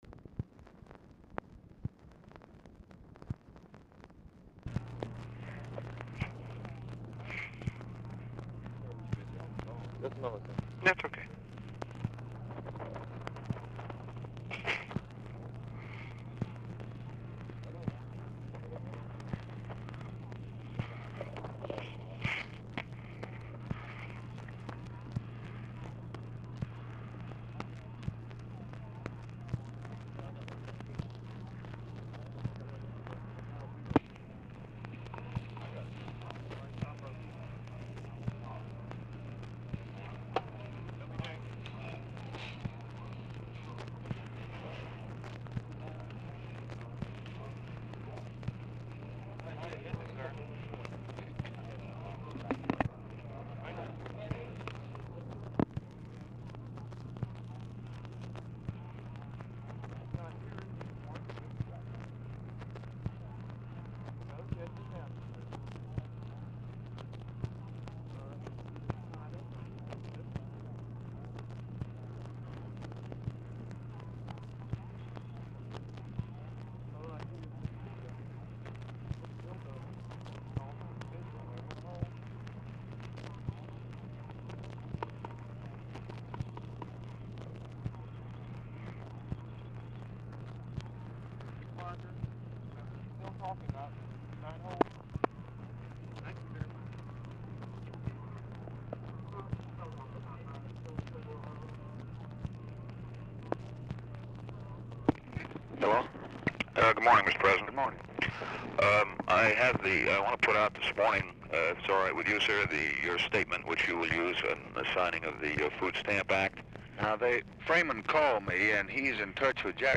INAUDIBLE OFFICE CONVERSATION PRECEDES CALL; KILDUFF ON HOLD 1:42
Format Dictation belt
Location Of Speaker 1 LBJ Ranch, near Stonewall, Texas
Specific Item Type Telephone conversation